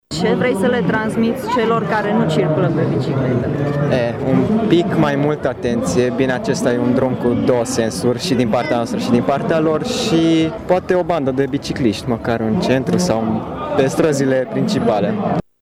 Unul dintre participanţi spune că ar fi cazul ca şi la Tîrgu-Mureş să existe piste pentru biciclete, mai ales în zonele centrale: